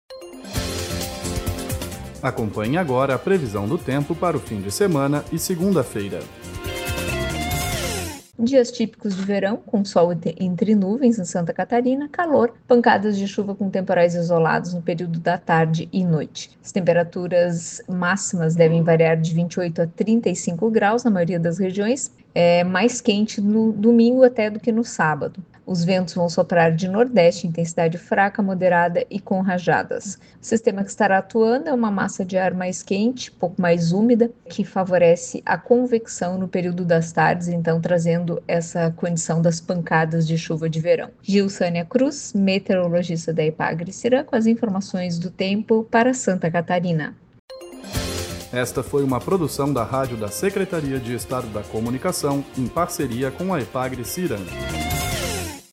Previsão do tempo para o fim de semana e segunda-feira, 15/02 a 17/02/2025